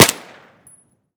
m4a1_sil-3.ogg